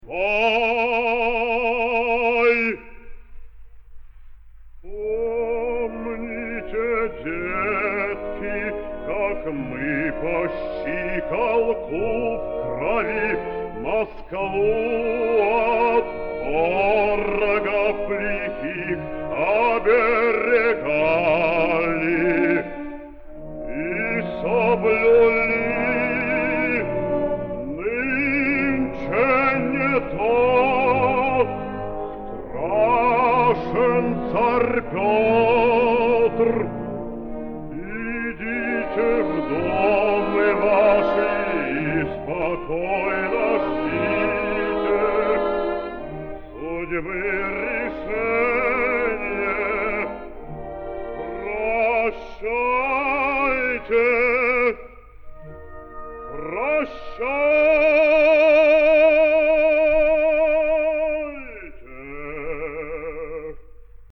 Борис Фрейдков - Обращение Ивана Хованского к стрельцам (М.П.Мусоргский. Хованщина, 3 д.) (1946)